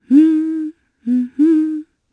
Demia-Vox_Hum_jp_b.wav